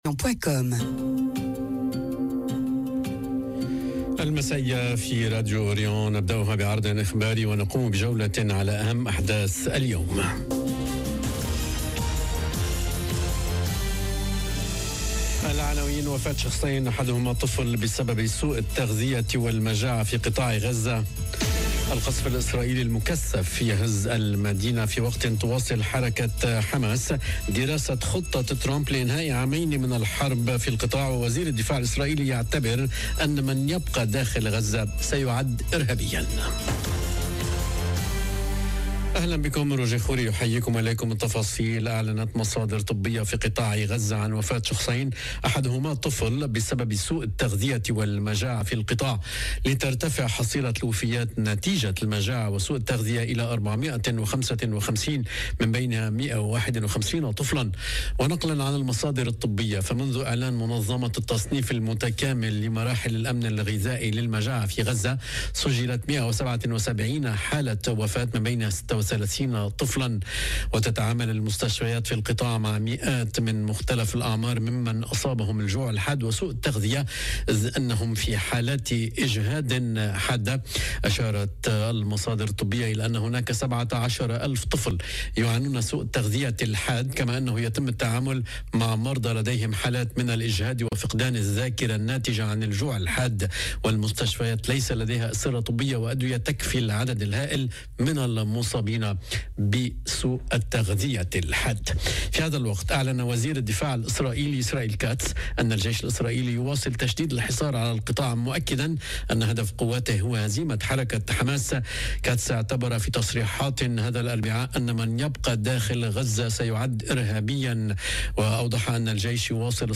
نشرة أخبار المساء: القصف الإسرائيلي المكثّف يهزّ مدينة غزة في وقت تواصل حركة حماس دراسة خطة ترامب لإنهاء عامين من الحرب في القطاع...